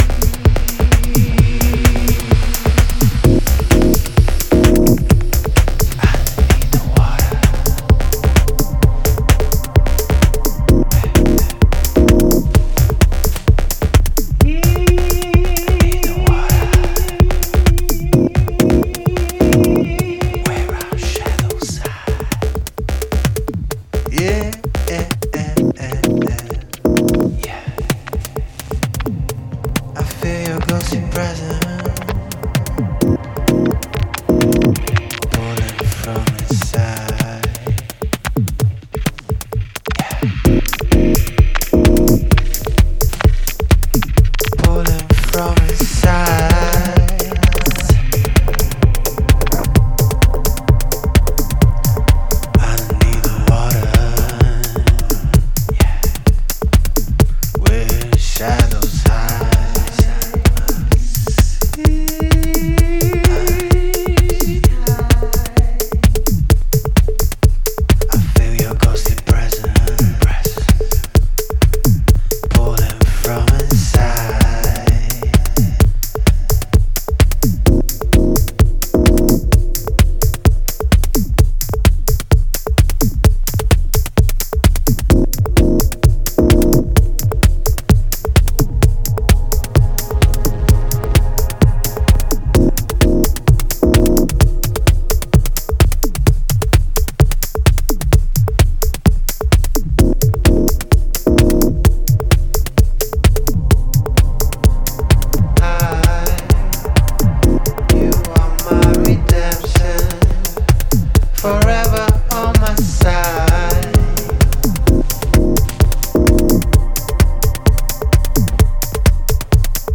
スポークン・ワードやシンセパッド、電化パーカッションの響きが幻想的なミニマル・ハウス秀作です。